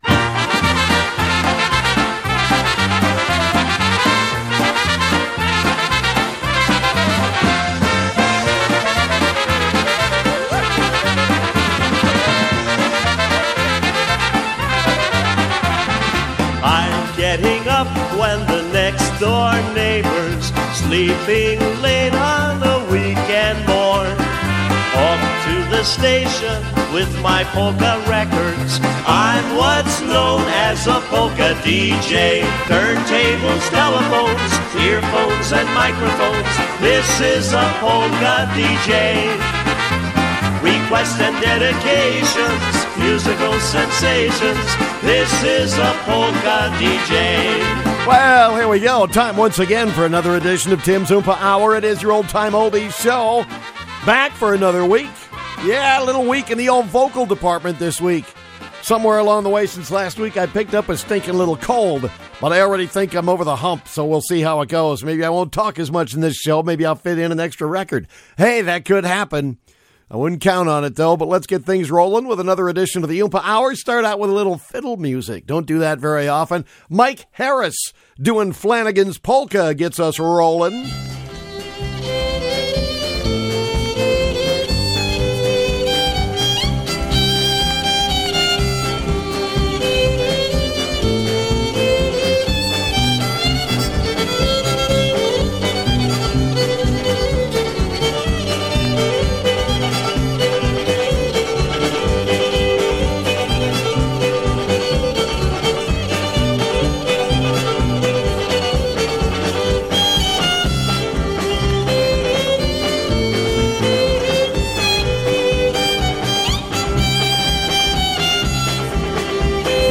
They're oldies because they all come from phonograph records!
You might hear a scratchy record now and then but that's what makes it fun!
The show is produced weekly at Iron Range Country Radio KEBS-AM 1620 on the Iron Range in Bovey, Minnesota where the show can he heard Saturday evenings at 7 PM and Sunday afternoons at 3 PM, and also on many affiliate stations all over the country.